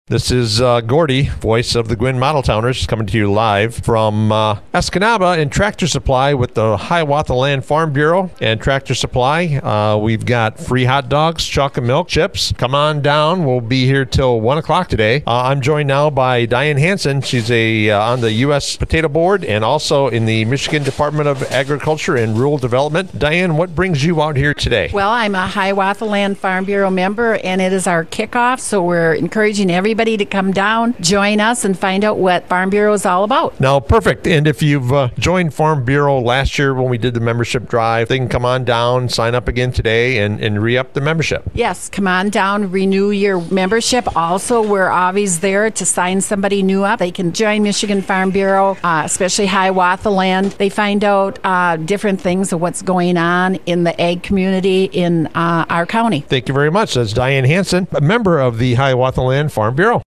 It looked to be a hectic day, as more and more people flooded into the Escanaba Tractor Supply Company’s showroom!